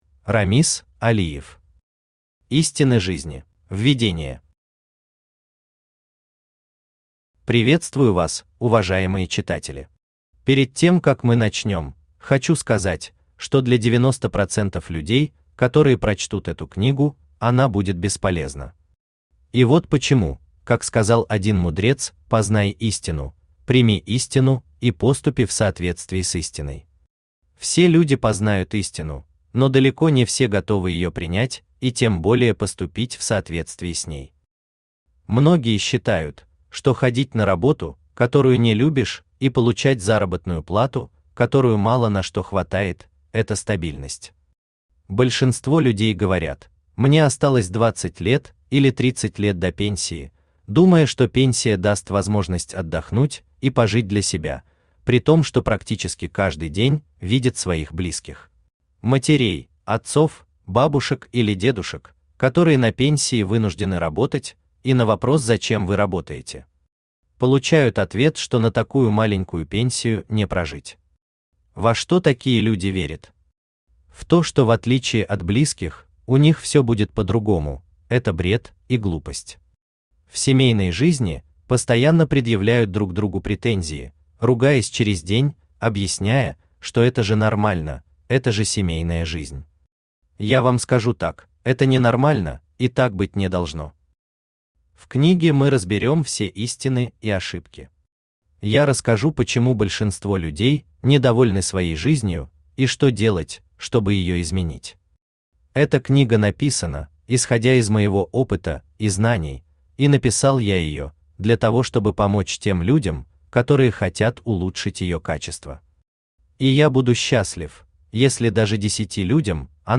Аудиокнига Истины жизни | Библиотека аудиокниг
Aудиокнига Истины жизни Автор Рамиз Видадиевич Алиев Читает аудиокнигу Авточтец ЛитРес.